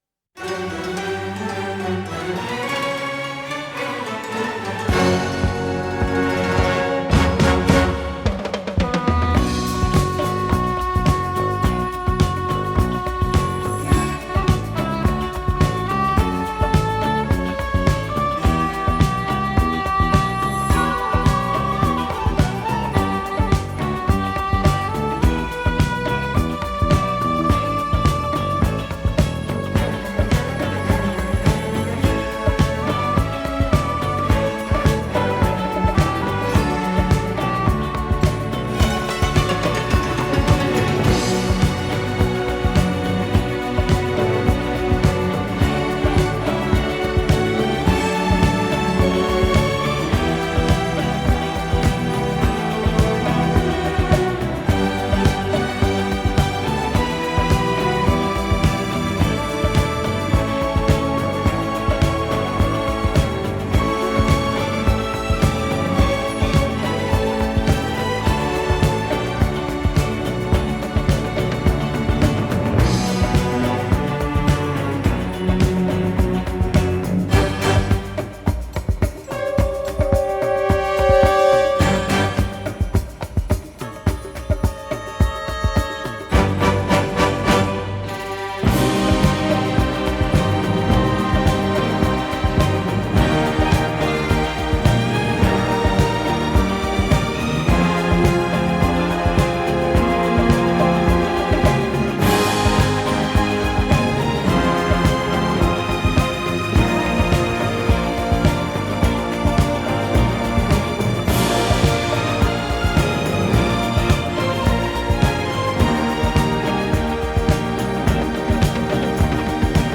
موسیقی اینسترومنتال موسیقی بیکلام